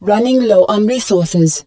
marine_lowresources.wav